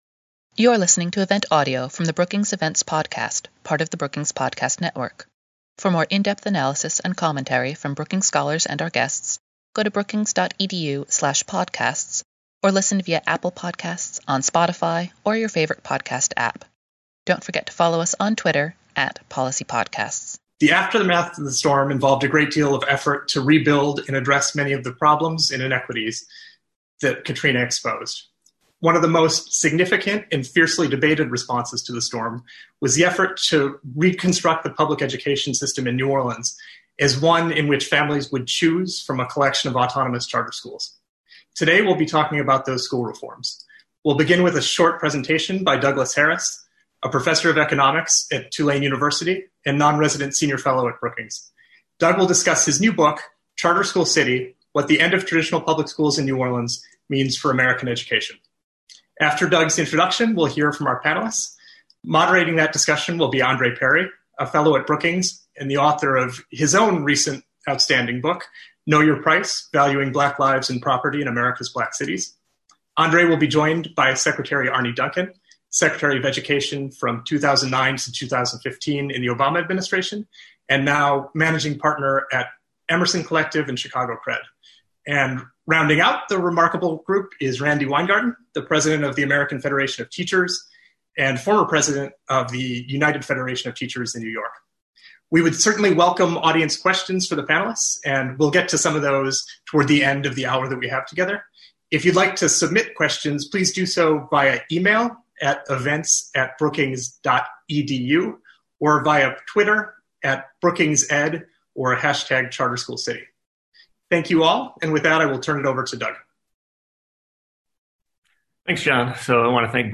On Sept. 2, the Brown Center for Education Policy at Brookings hosted a webinar discussion on different perspectives about charter schools.